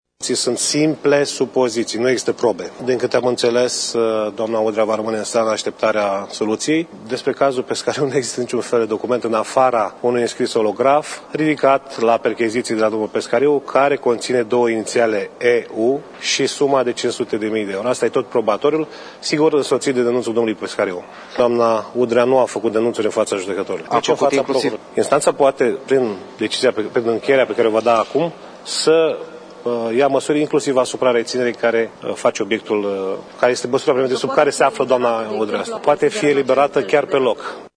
În urmă cu puţin timp unul dintre avocaţii Elenei Udrea a declarat jurnaliştilor că probele împotriva clientei sale sunt simple supoziţii:
avocat.mp3